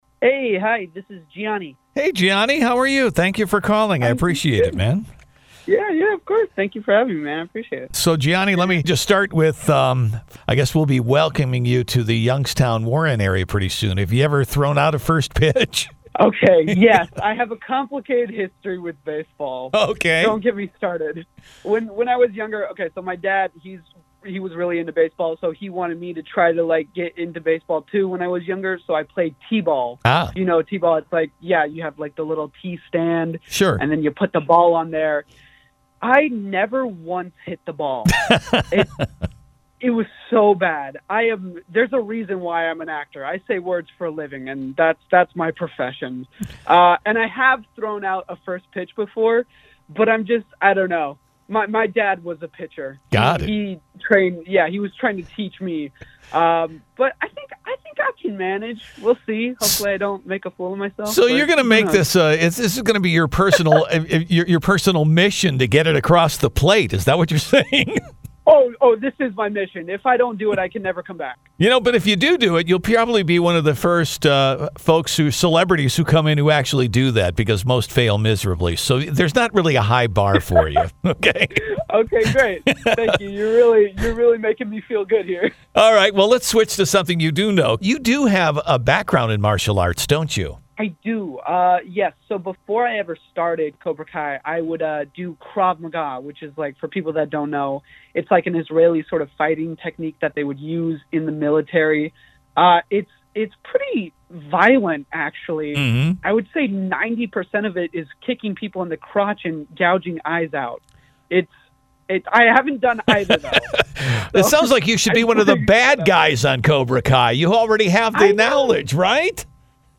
spoke with Gianni on the Morning Show about the upcoming event at Eastwood Field.